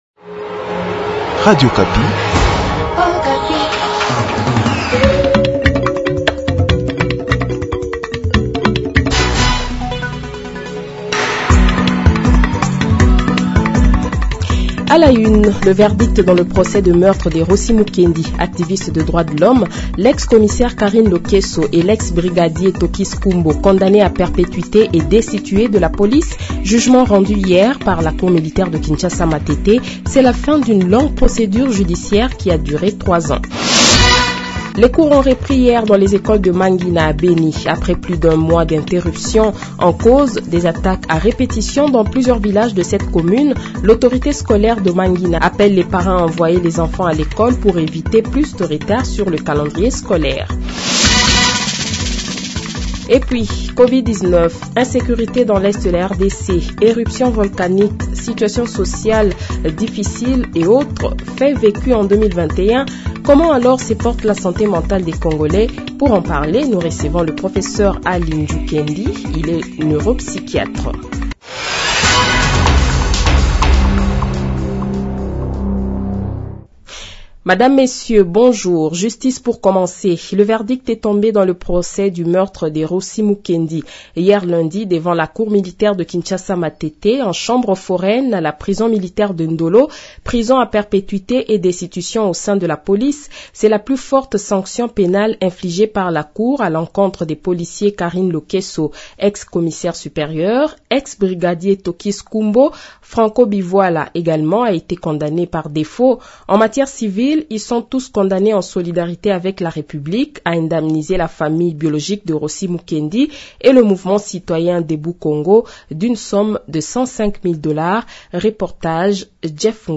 Le Journal de 7h, 11 Janvier 2022 :